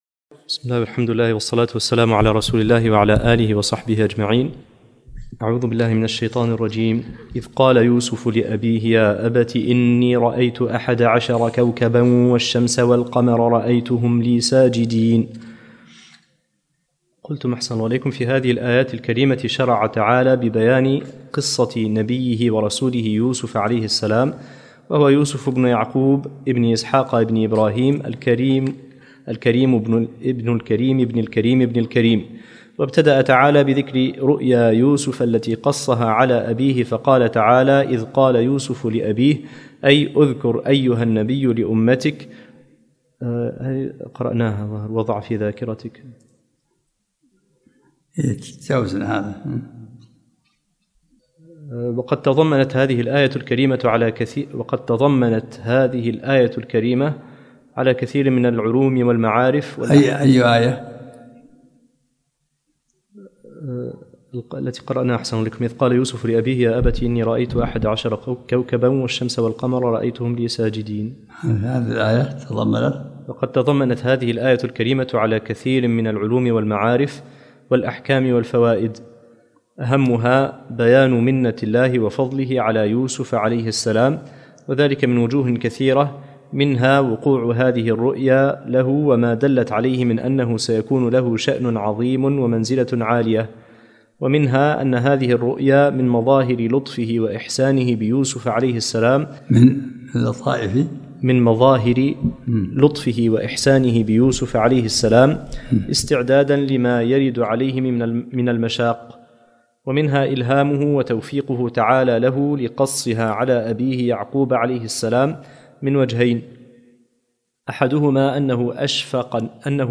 الدرس السادس عشرمن سورة يوسف